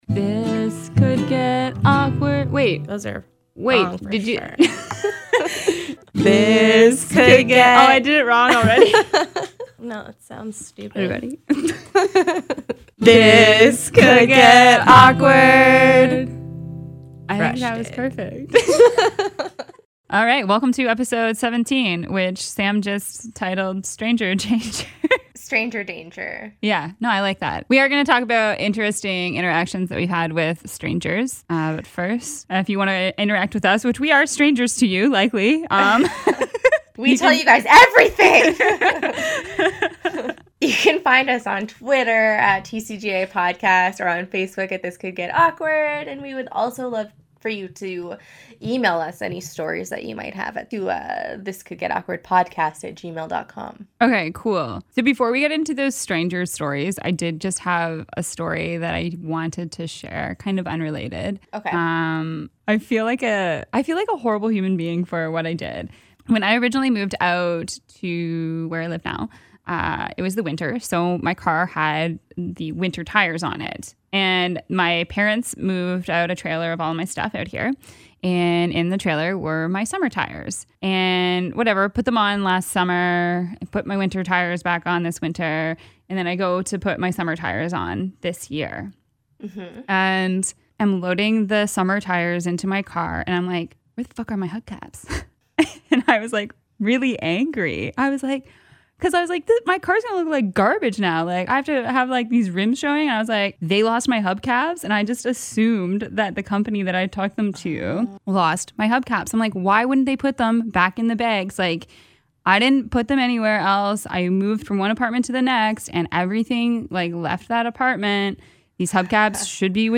This Could Get Awkward » Page 49 of 65 » A podcast by two awesome women.